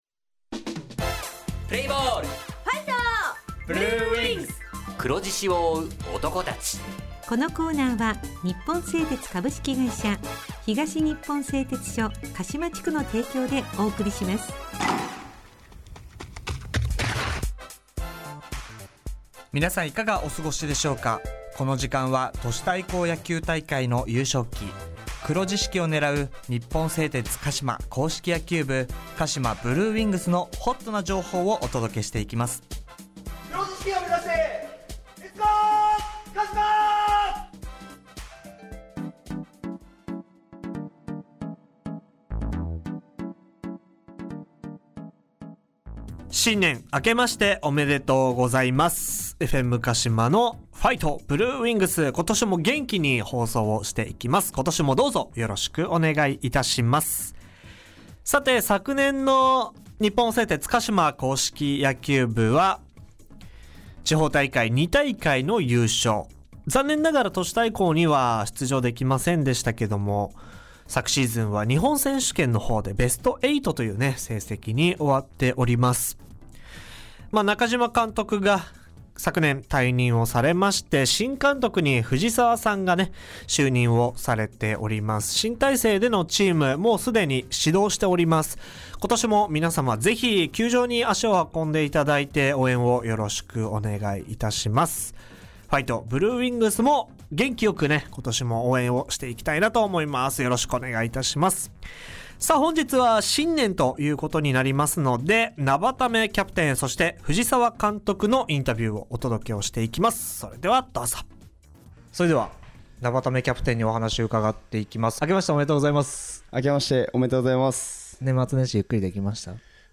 インタビュー
地元ＦＭ放送局「エフエムかしま」にて鹿島硬式野球部の番組放送しています。